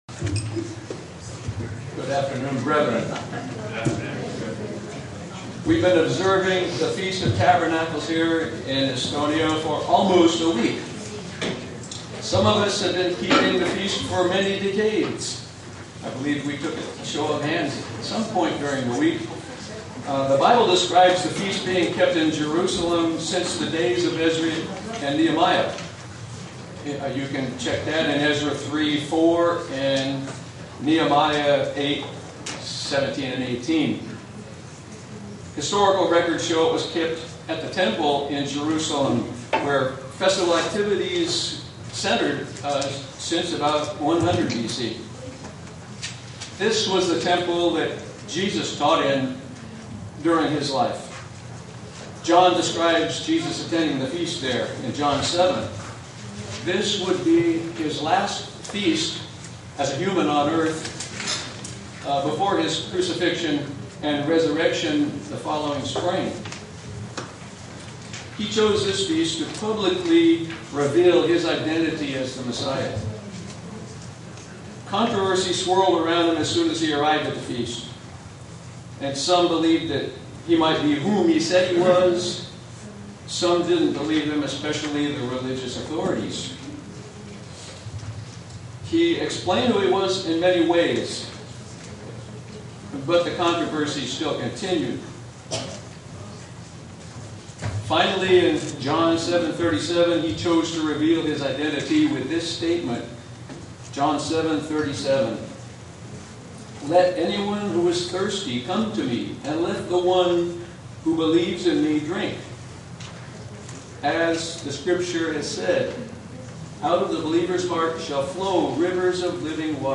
Print The seventh day of the Feast of Tabernacles in Estonia SEE VIDEO BELOW UCG Sermon Studying the bible?
Given in Buffalo, NY